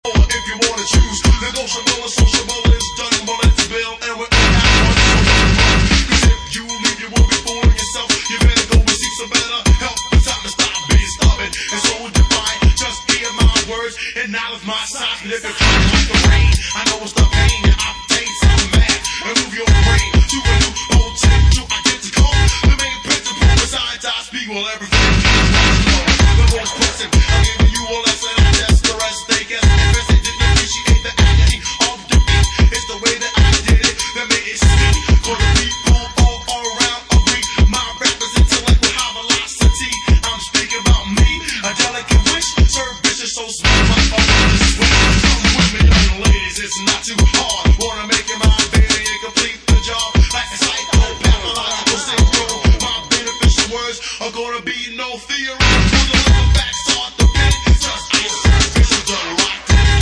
Classic hiphop lp repressed in original fullcolor sleeve..